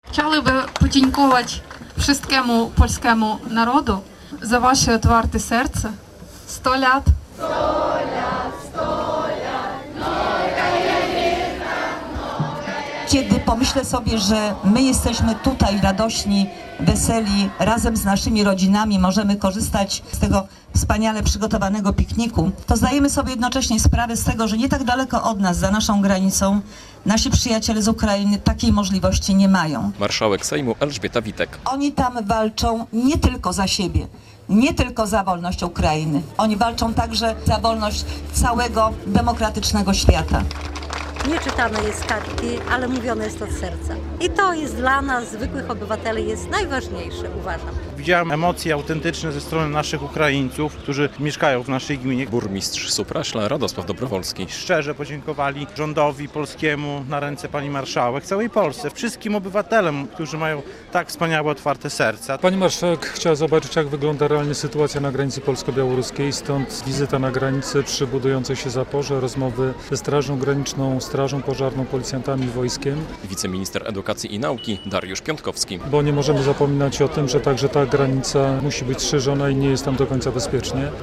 Nasz rząd stara się zapewnić wszystkim obywatelom bezpieczeństwo, w centrum zawsze stawia rodzinę - mówiła marszałek Sejmu Elżbieta Witek, która w poniedziałek (27.06) w Supraślu koło Białegostoku wzięła udział w Pikniku Rodzinnym zorganizowanym przez gminę Supraśl we współpracy z urzędem marszałkowskim.
relacja
Spotkanie z marszałek Elżbietą Witek zorganizowano na rynku w Supraślu.